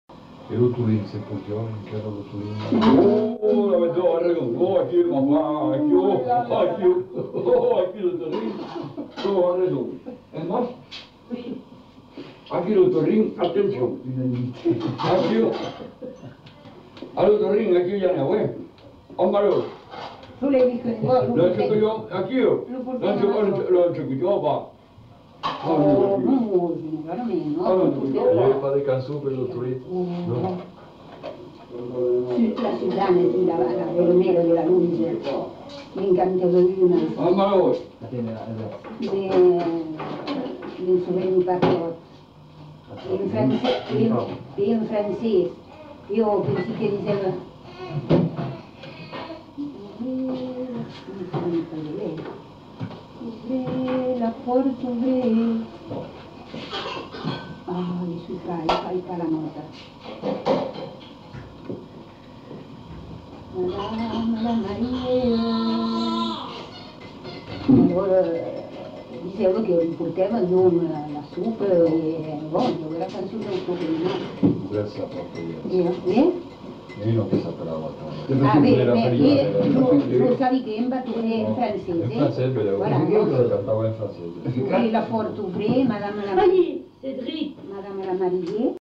Aire culturelle : Marmandais gascon
Lieu : Tonneins
Genre : témoignage thématique